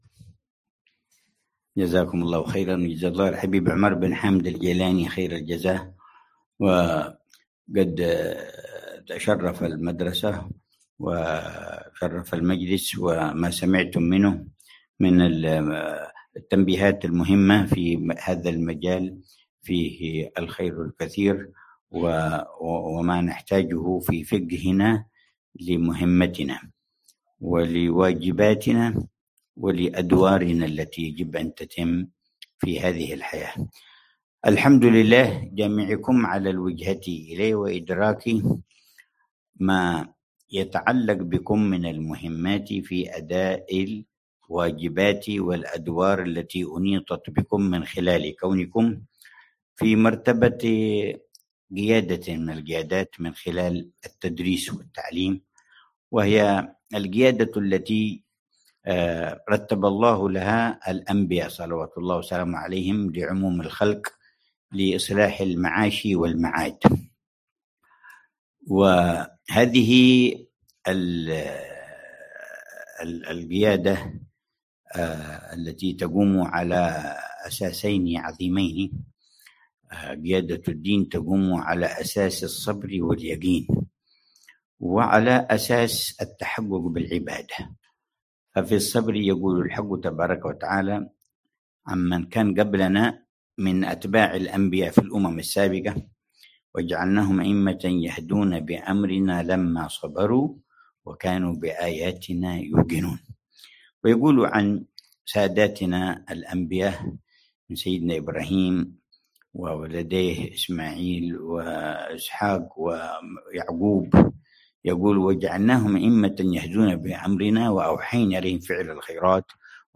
محاضرة العلامة الحبيب عمر بن حفيظ، في مدرسة الجنيد الإسلامية، سنغافورة، الخميس 24 ربيع الثاني 1447هـ بعنوان: